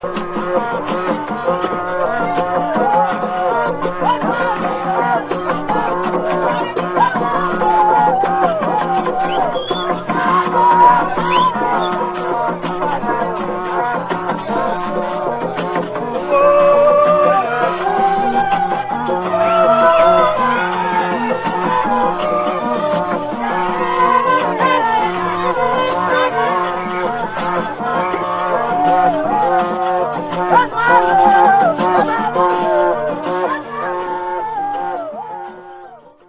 Trompetas naturales
Intérpretes: Grupo Gagá
Ensamble: Vaccines, trompetas, maracas, voces
Característica: Toques con los que se acompañan las ceremonias del culto gagá, rituales que se realizan durante carnaval y cuaresma.
Grupo Cultural: Afrodominicano
Procedencia, año: Batey La Isabela, Prov. Baoruco, República Dominicana, 1982